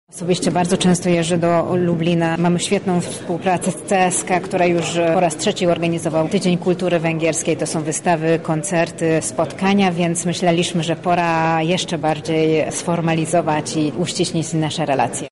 Otwarcie odbyło się dziś w Trybunale Koronnym.
Sam fakt, że w 1956 roku jednym z najbardziej pomocnych i solidaryzujących się z nami był Lublin, daje fundament pod rozszerzenie relacji – mówi ambasador Węgier w Polsce Orsolya Kovács: